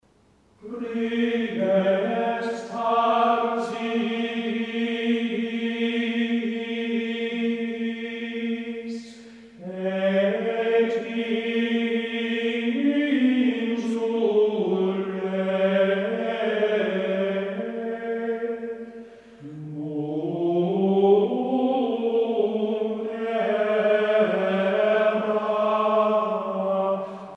Musique médiévale